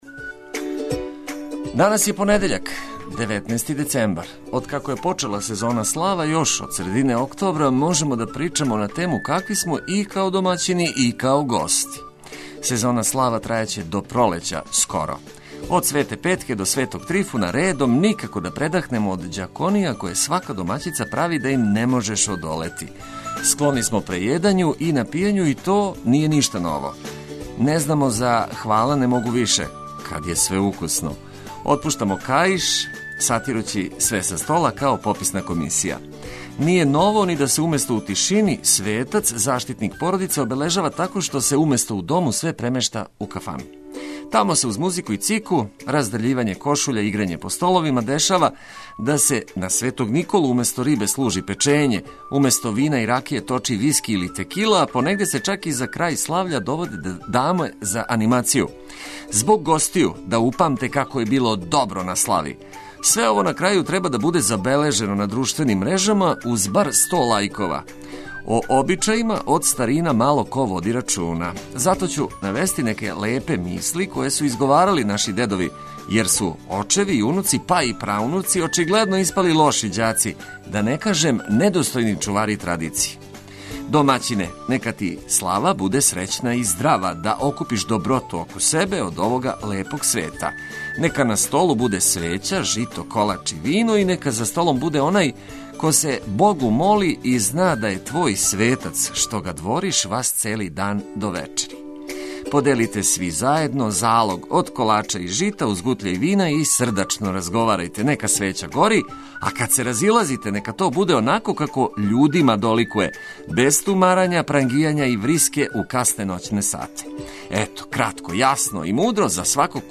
Заједничко им је да сви могу да се током јутра, ако желе, пробуде уз корисне приче и одличну музику из нашег студија.